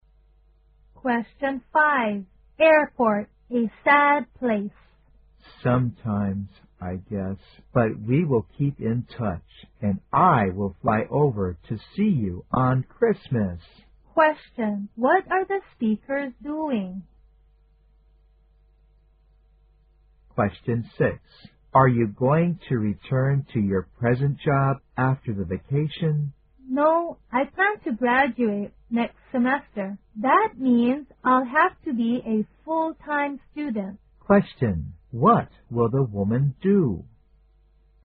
在线英语听力室116的听力文件下载,英语四级听力-短对话-在线英语听力室